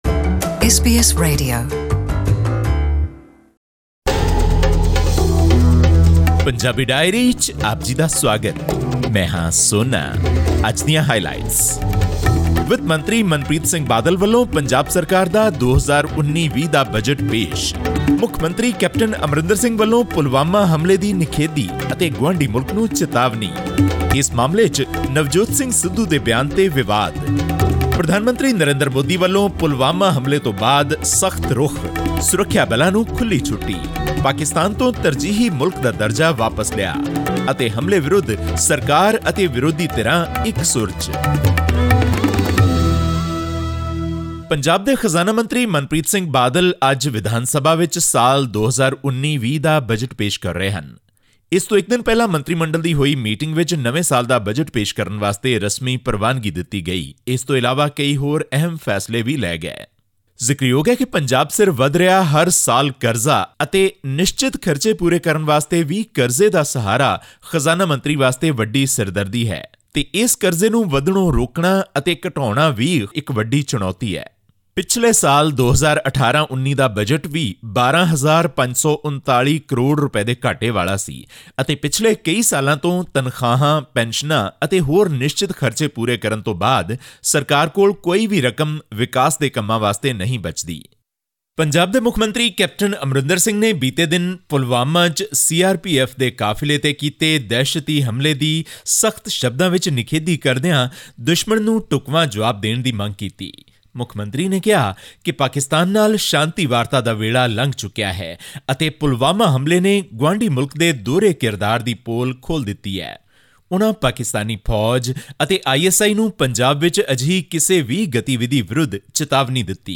Here is a news-wrap of the most important happenings this week in Punjab.